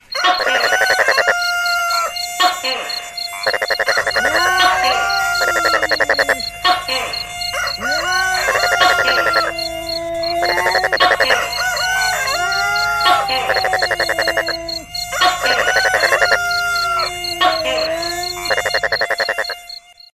Genre: Nada dering alarm